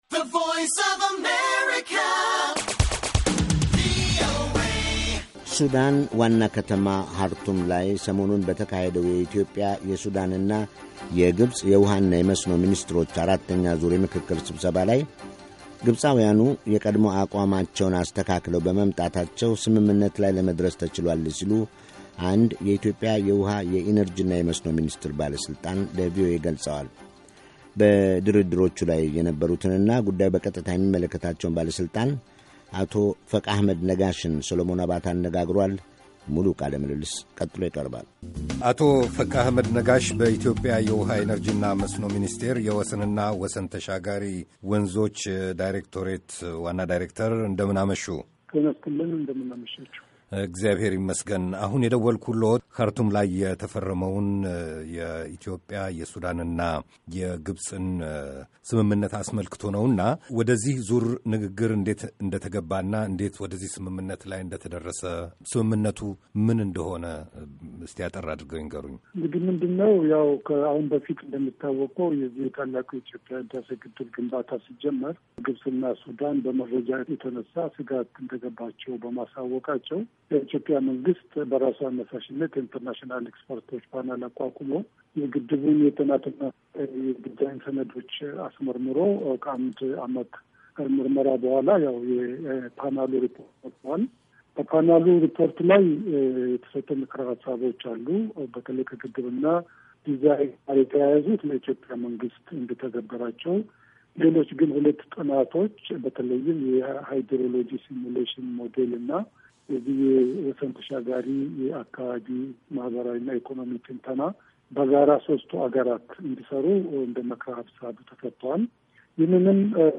የኢትዮጵያ፣ የሱዳንና የግብፅ ስምምነት - ቃለምልልስ